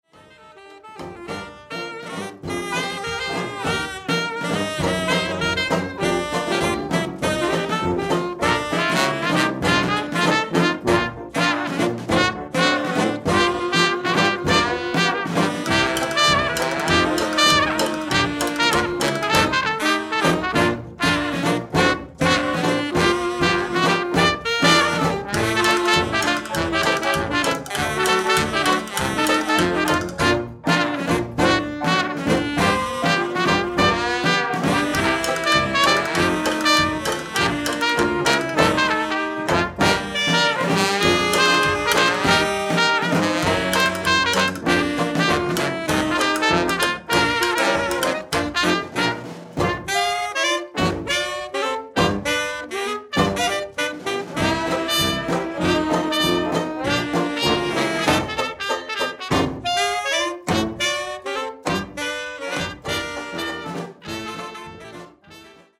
Gatsby Rhythm Kings | Gatsby Era Jazz Band | 1920s Jazz Big Band
Melbourne’s very own 1920’s orchestra.
Gatsby Rhythm Kings Jazz Band are Melbourne's only authentic 1920's Jazz Big Swing band